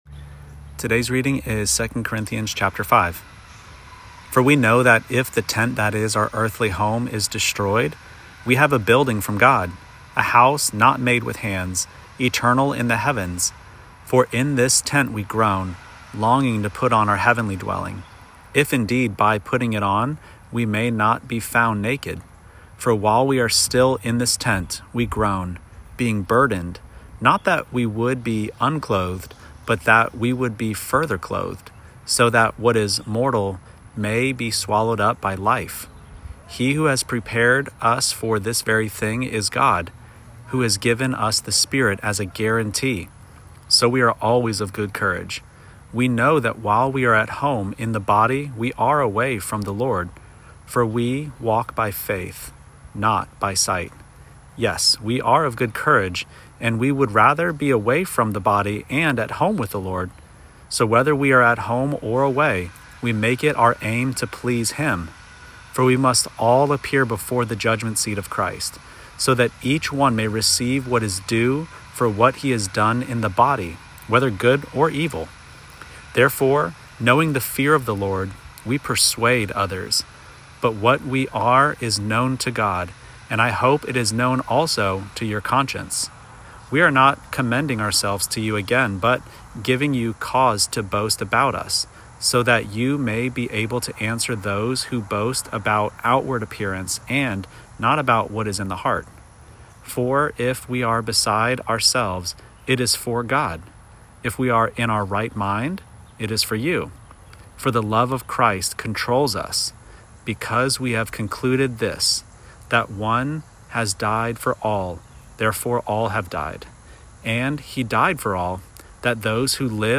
Daily Bible Reading (ESV) September 12: 2 Corinthians 5 Play Episode Pause Episode Mute/Unmute Episode Rewind 10 Seconds 1x Fast Forward 30 seconds 00:00 / 3:08 Subscribe Share Apple Podcasts Spotify RSS Feed Share Link Embed